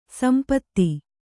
♪ sampatti